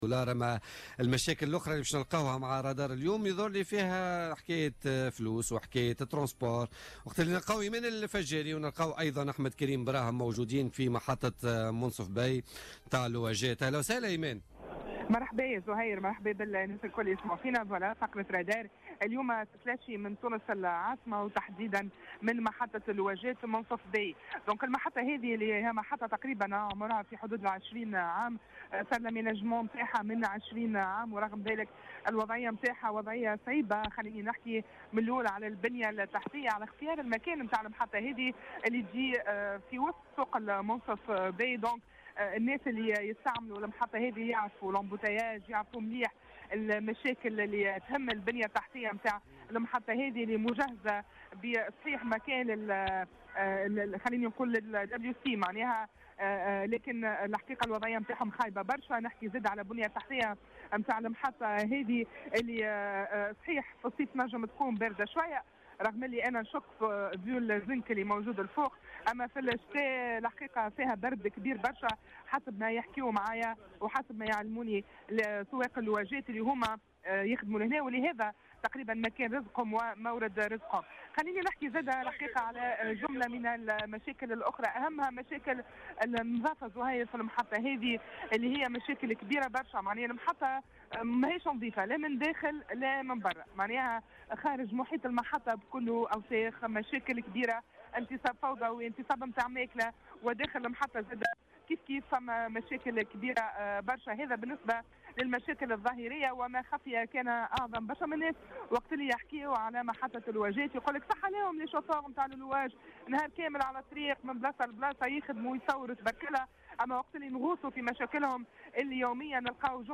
الرادار من محطة المنصف باي بالعاصمة
وقد تحدث عدد من سواق سيارات الأجرة عن غياب التنظيم والنظافة في المحطة والذي بات يهدد مورد رزقهم حيث يعاني أصحاب الرخص من ظاهرة "الفلاقة" الذين يشاركونهم عملهم دون وجه حق مطالبين بضرورة تدخل السلط المعنية لتنظيم القطاع.